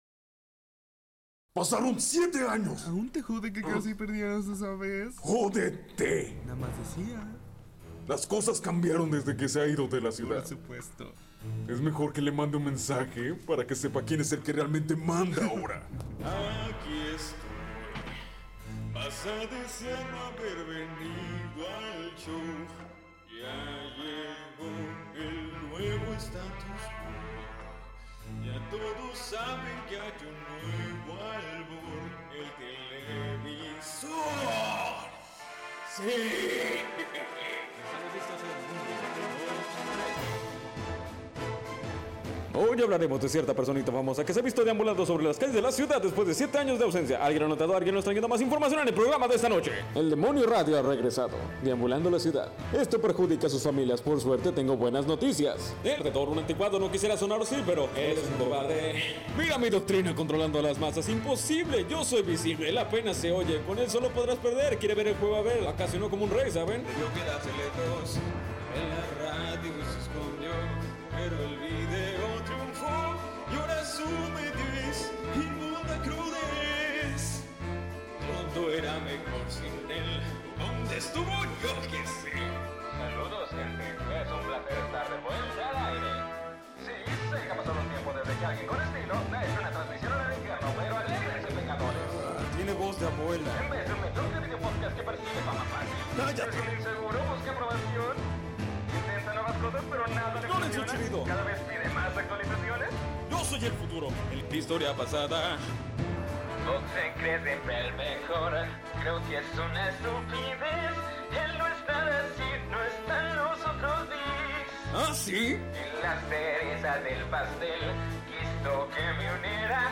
cover versión 2